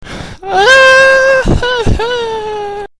cry